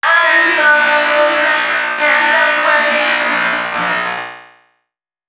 005 male.wav